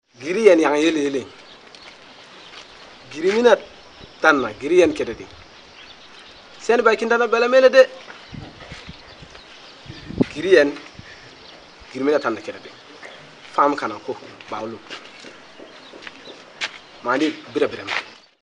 Malin jasotako audioa. Ba al du euskararekin antzekotasunik Dogon hizkuntzak?